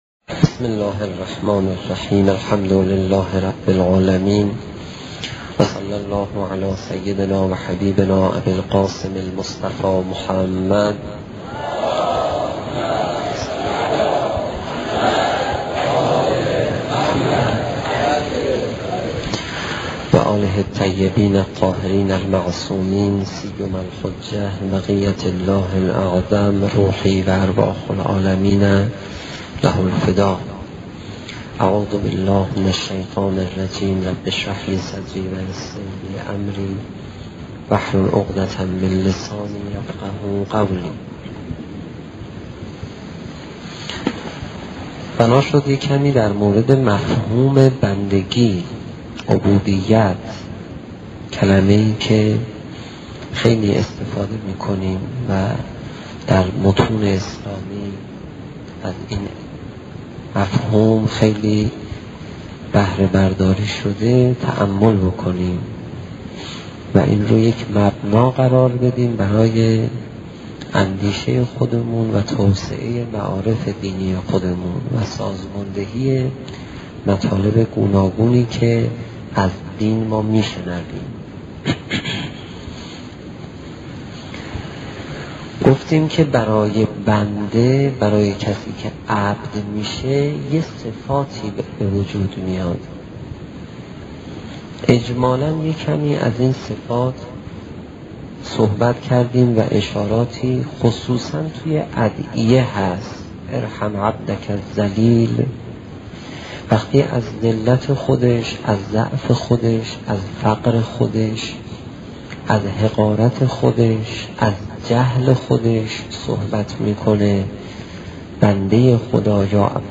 سخنرانی قدیمی استاد پناهیان